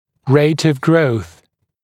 [reɪt əv grəuθ][рэйт ов гроус]скорость роста